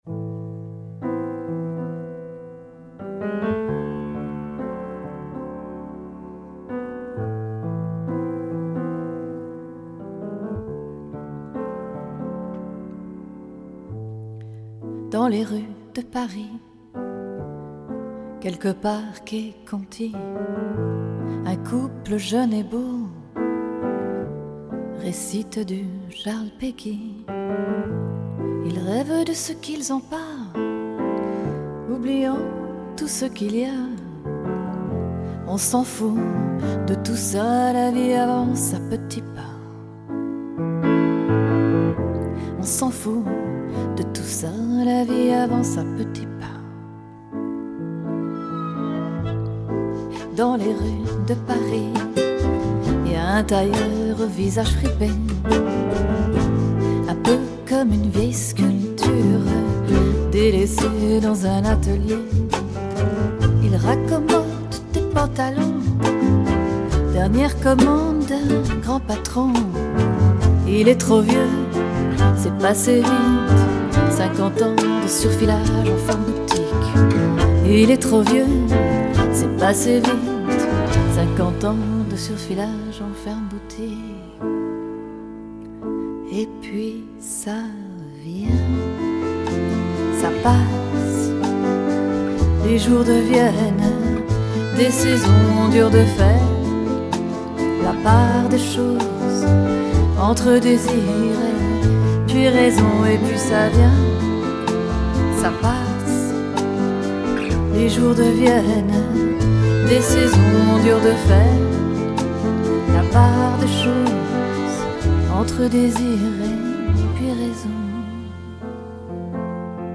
Piano and Vocals
Accordion